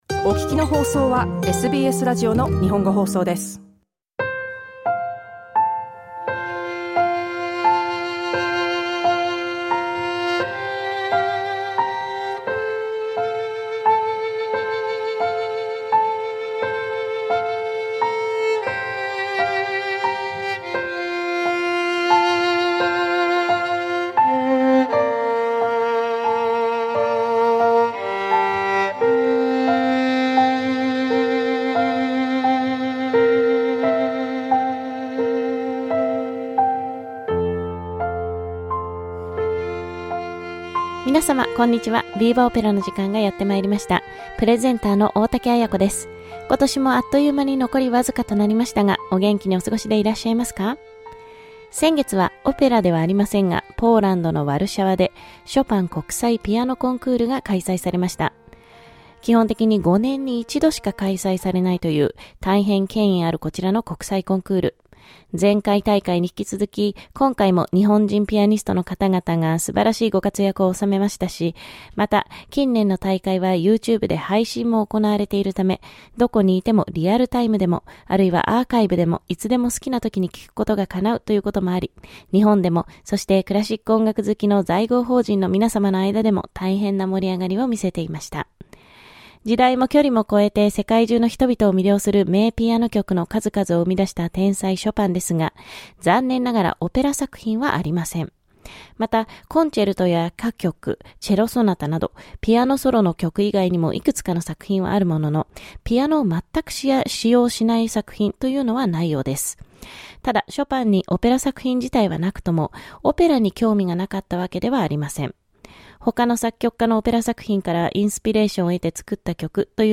先月ポーランドで開催されたショパン国際ピアノコンクールにちなんでの選曲。ショパンの「ラ・チ・ダレム変奏曲」の原曲、モーツァルトによる「お手をどうぞ」の二重唱です。
ラジオ番組で解説に続いて紹介するのは、オペラ歌手イルデブランド・ダルカンジェロ（ドン・ジョヴァンニ）とモイツァ・エルトマン（ツェルリーナ）による歌唱です。